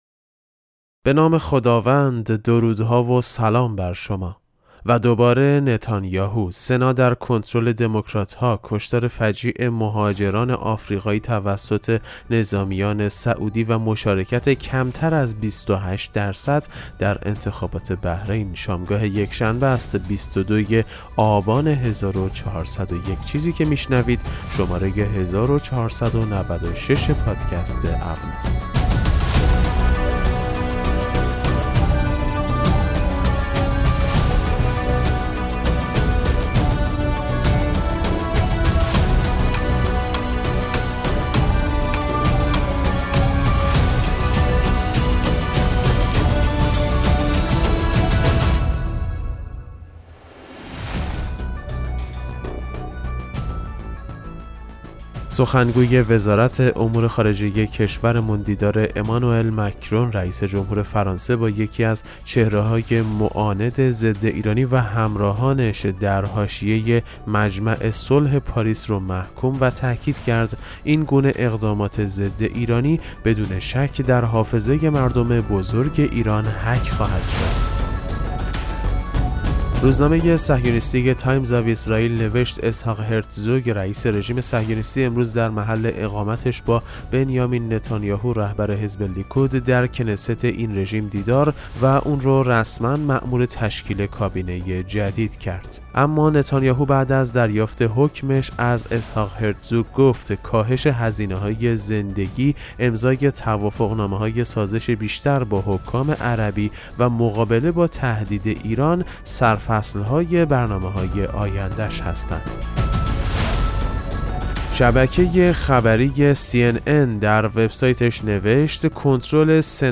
پادکست مهم‌ترین اخبار ابنا فارسی ــ 22 آبان 1401